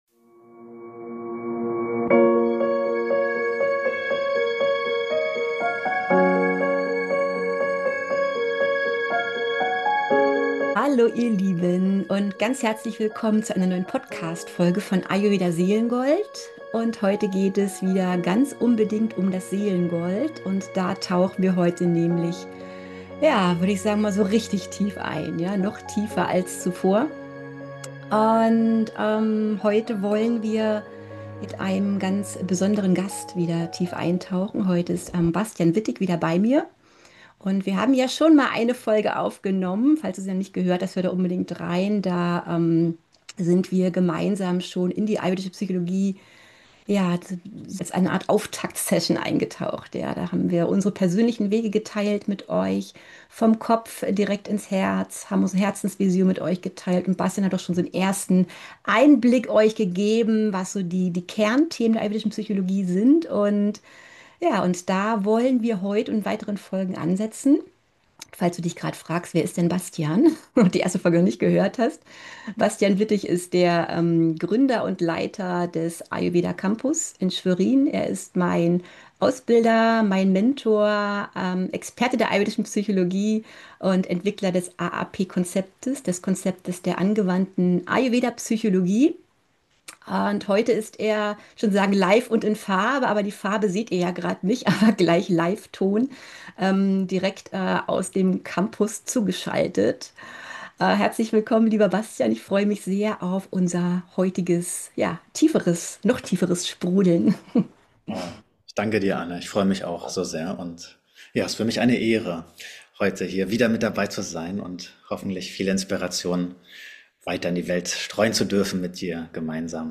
Ayurvedische Psychologie – Selbstwahrnehmung, Selbsterkenntnis, Selbstentfaltung – Im Interview